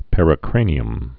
(pĕrĭ-krānē-əm)